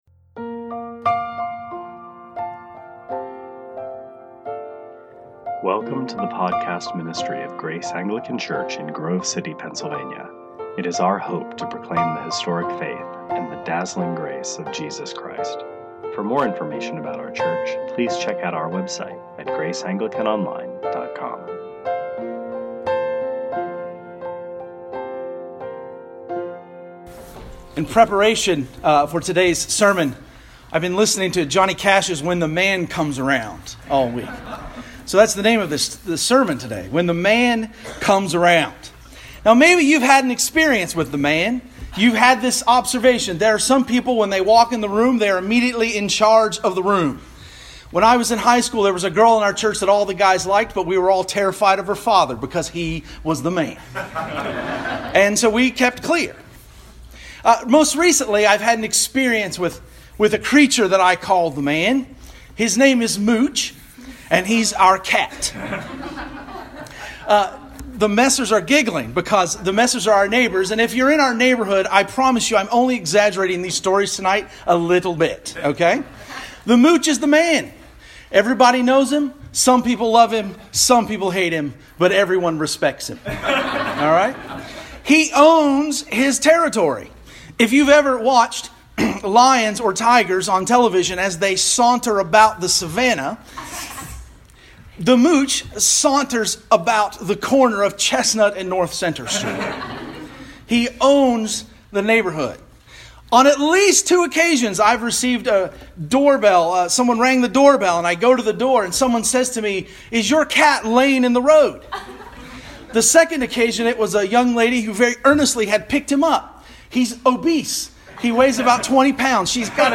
2018 Sermons